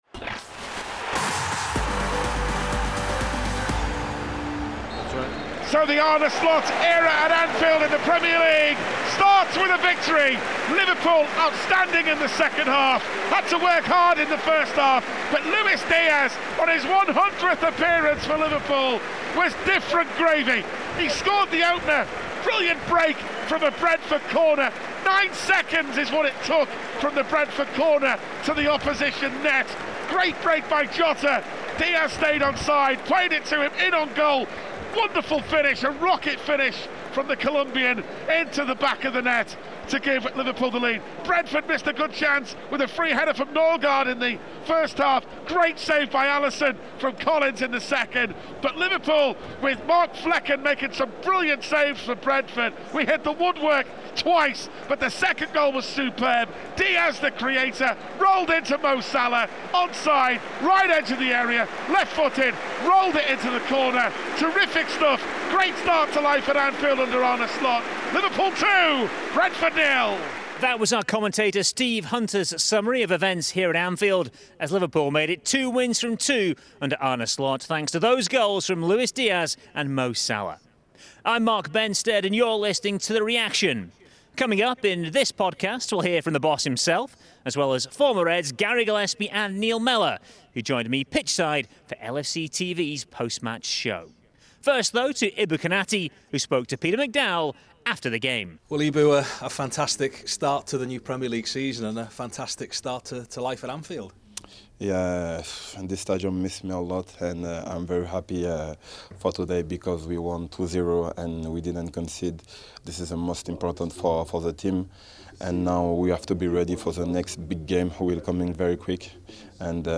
Arne Slot and Ibrahima Konaté reflect on the 2-0 victory over Brentford in the head coach’s first competitive home match in charge. Former Reds Gary Gillespie and Neil Mellor provide pitchside analysis of the key talking points from Anfield, after goals from Luis Díaz and Mo Salah saw Liverpool secure six points from their opening two Premier League fixtures of the 2024-25 campaign.